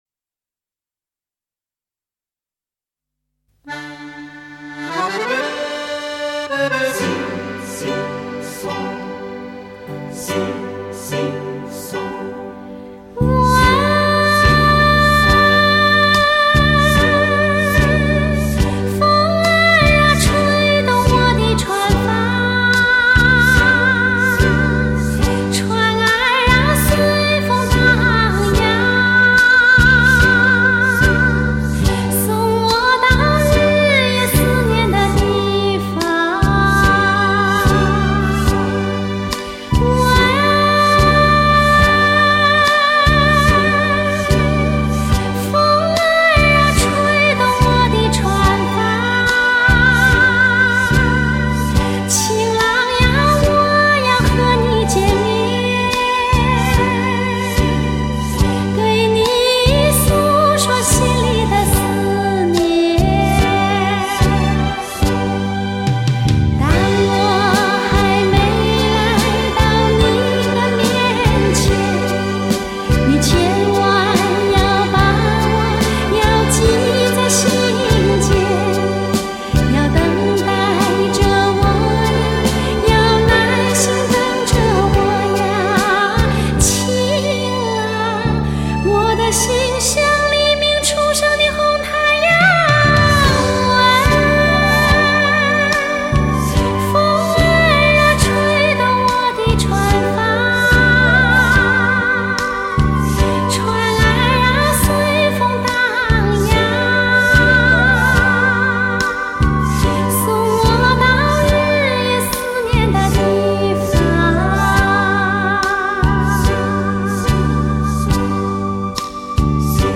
典范发烧人声HIFI经典音乐
人声